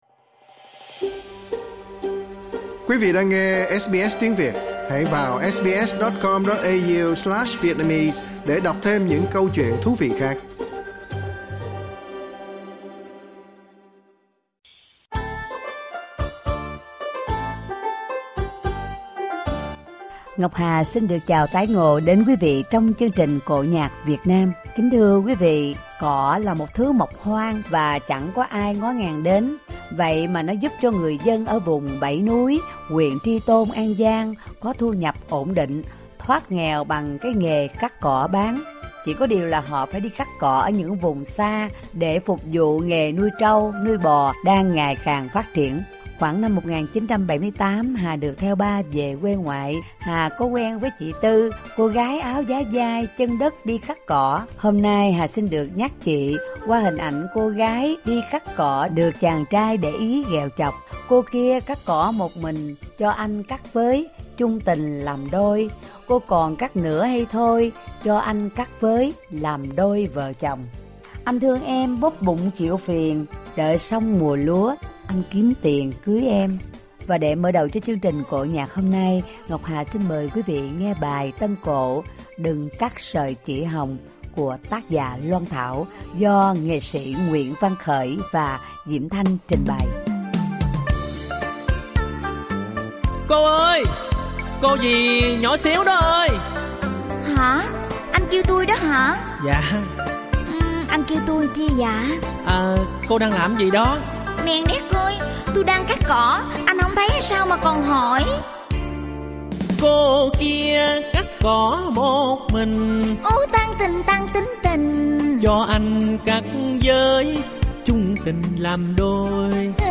Tân cổ
2 câu vọng cổ